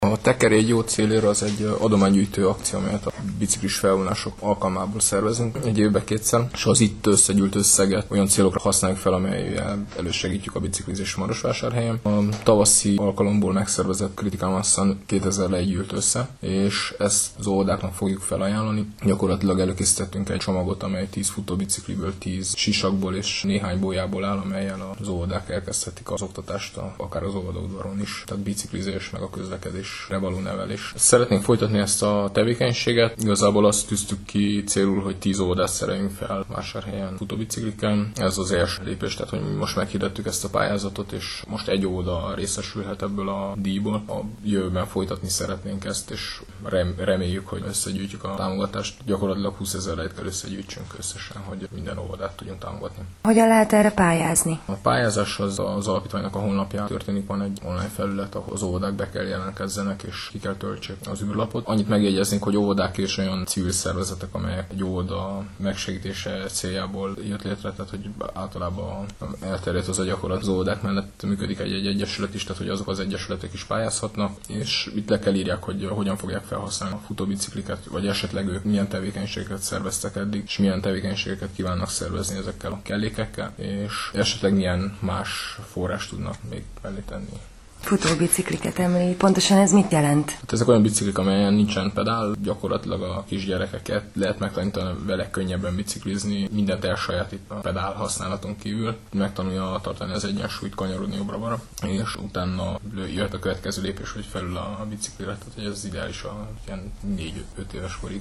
beszélgettünk